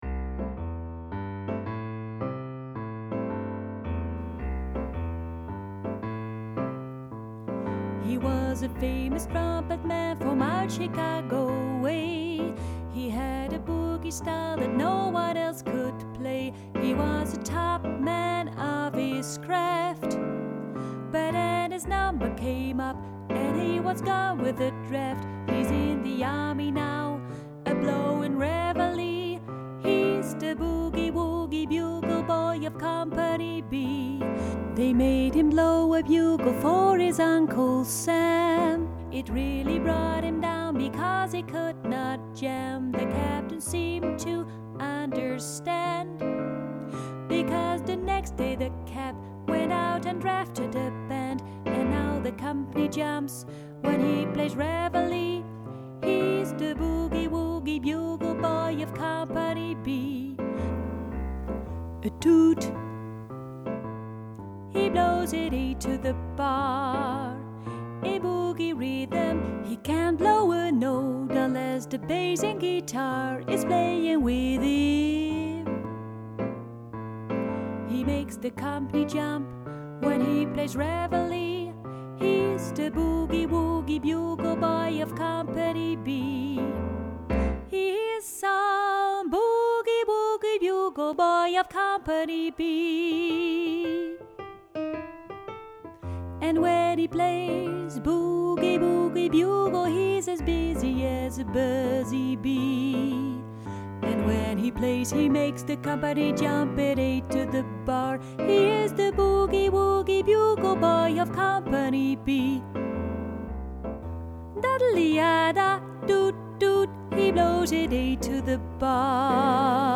bb-mezzo.mp3